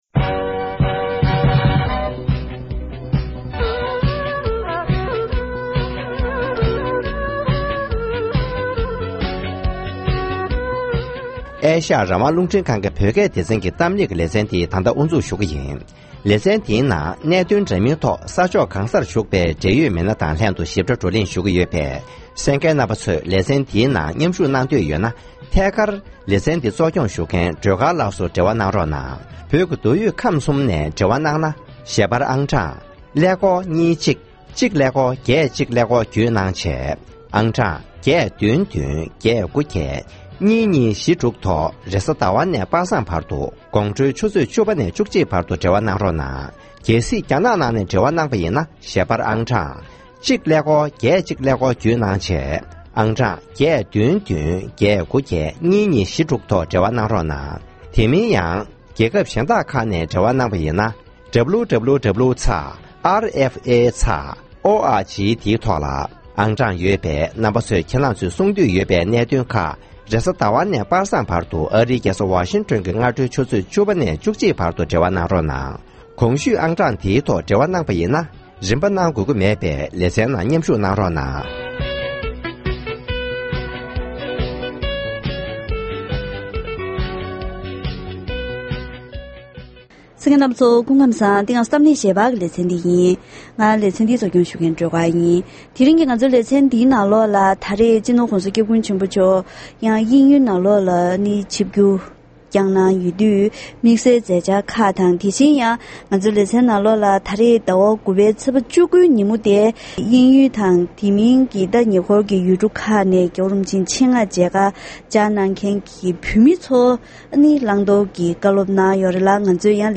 ༧གོང་ས་༧སྐྱབས་མགོན་ཆེན་པོ་མཆོག་ནས་དབྱིན་ཡུལ་དང་ཉེ་འཁོར་གྱི་ཡུལ་གྲུ་ཁག་ནས་ཕེབས་མཁན་ཚོར་མཇལ་ཁ་དང་བཀའ་སློབ་གནང་ཡོད།